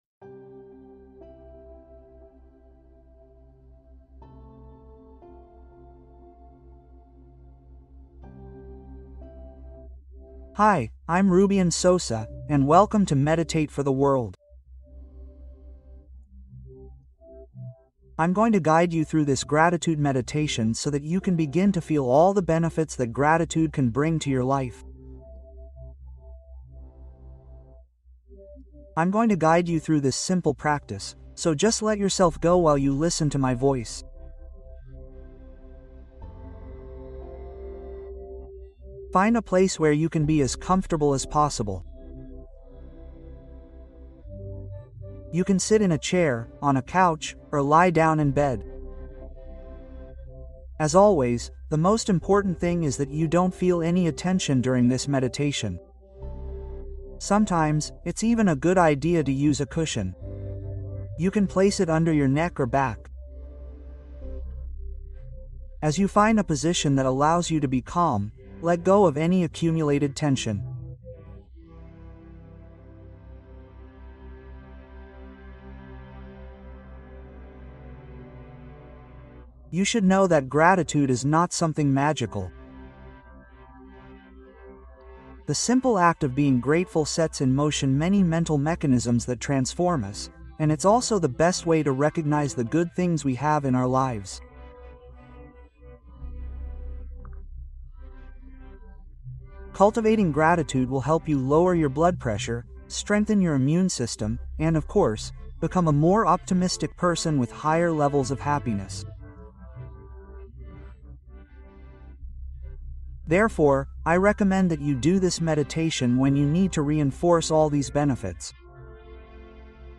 Conecta con Tu Guía Interna: Meditación para Elevar Tu Estado Interior